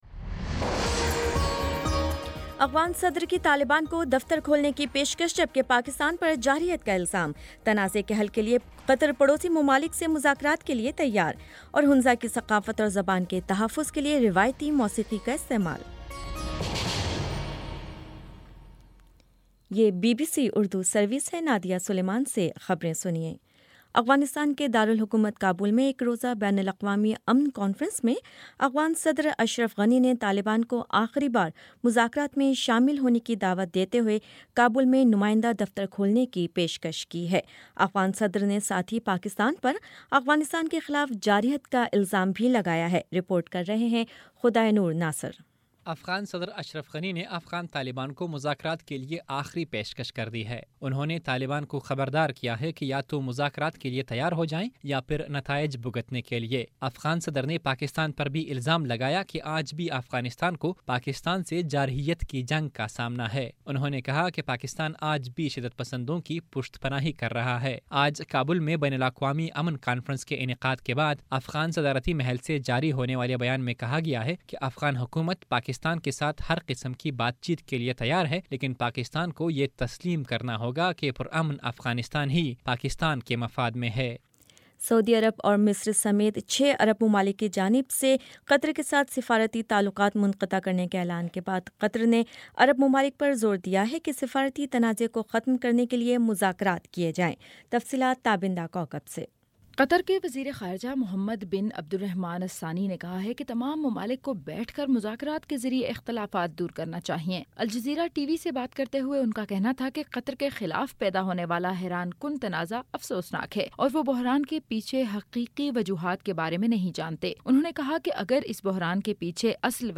جون 06 : شام پانچ بجے کا نیوز بُلیٹن